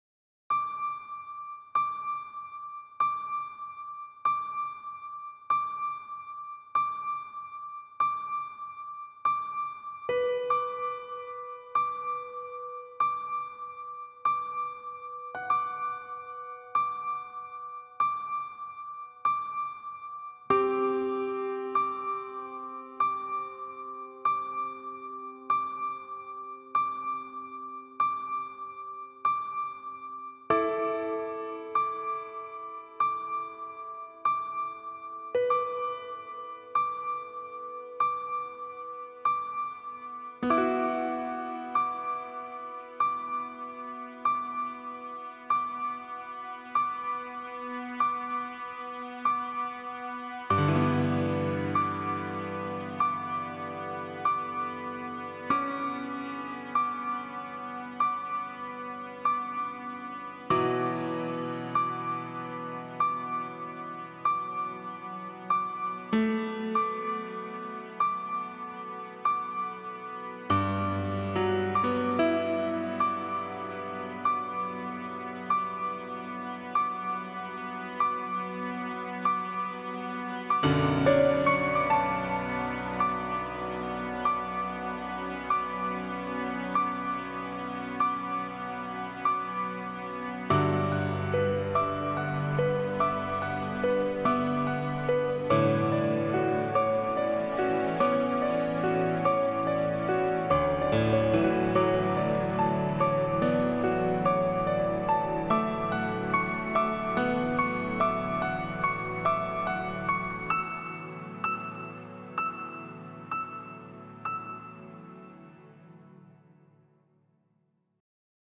Piano x Cello